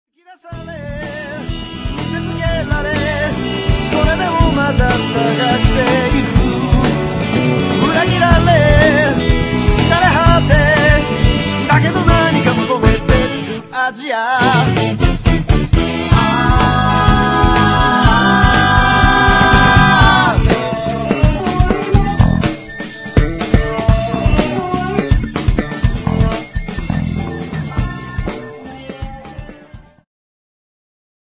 ジャンル POPS系
Progressive